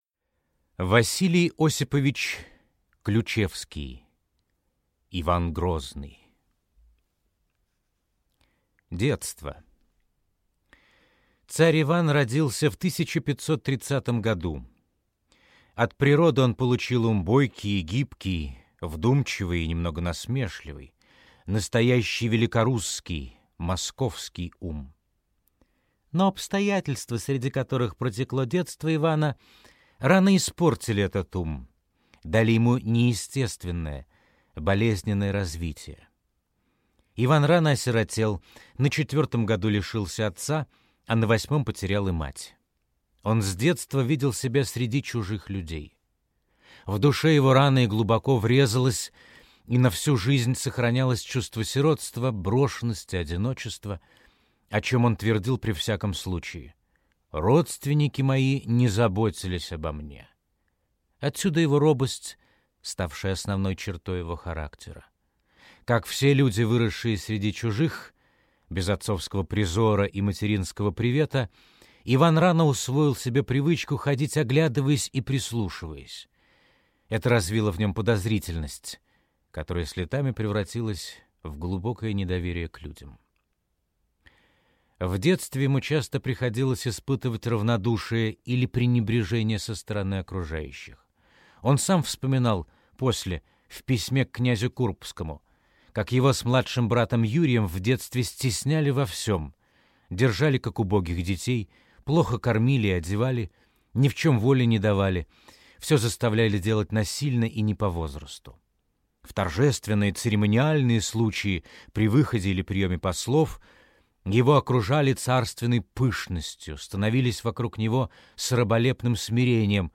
Аудиокнига Иван Грозный | Библиотека аудиокниг